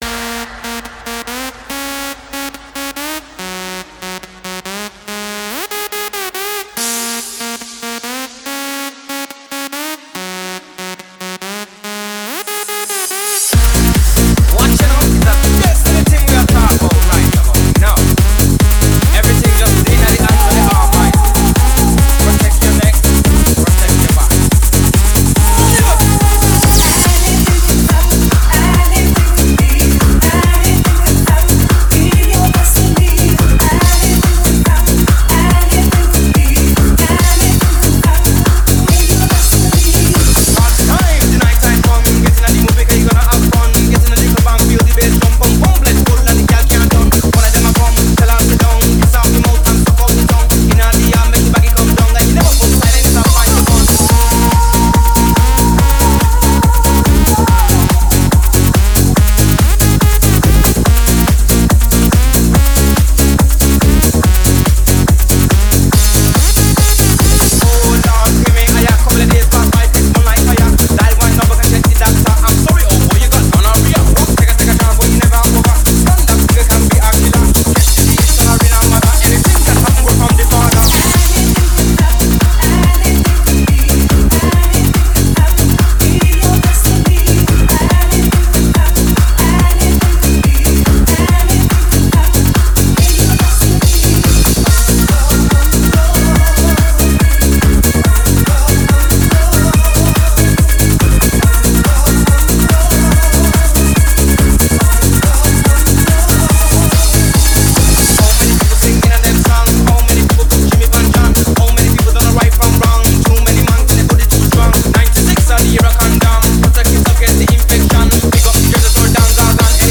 mp3 0:00 EuroDance_ Скачать